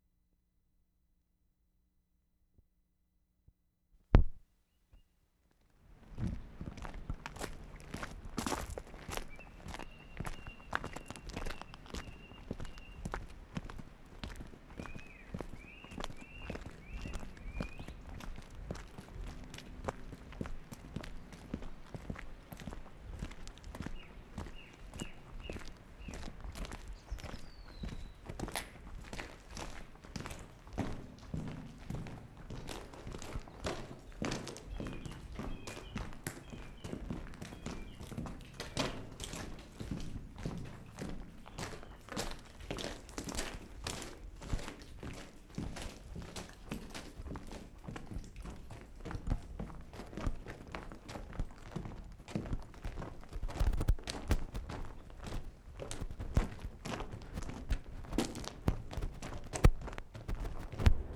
B 3-6. DISUSED RAILWAY TUNNEL near Marsden
Entering railway tunnel, ambience change.